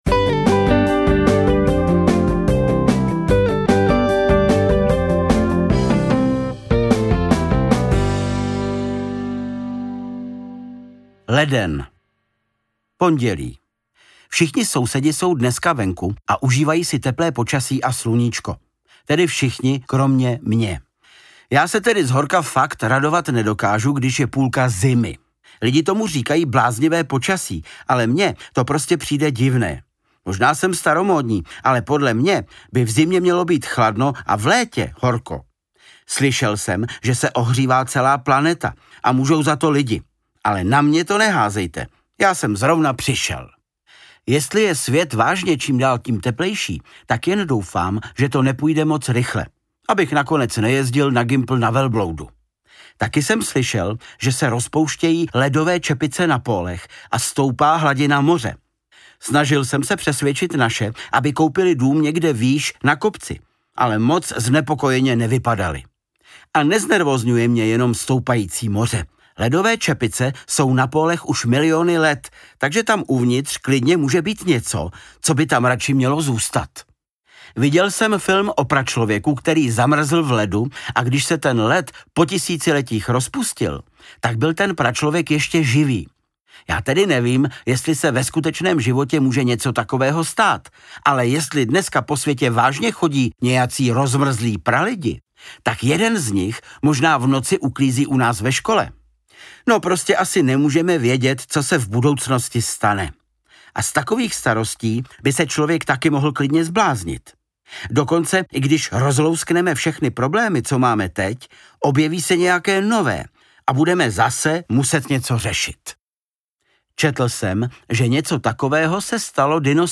Interpret:  Václav Kopta